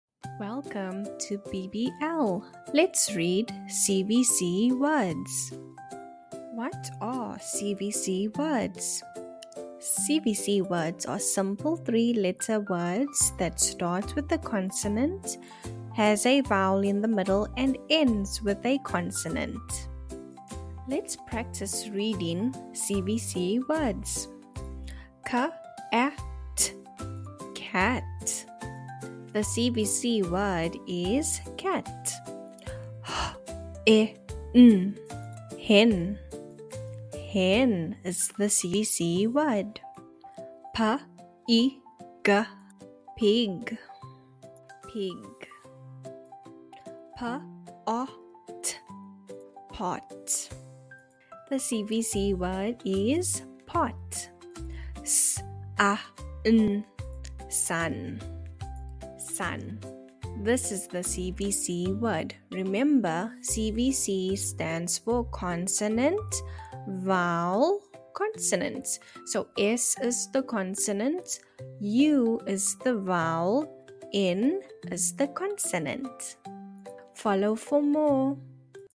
Fun Reading Lesson for Kids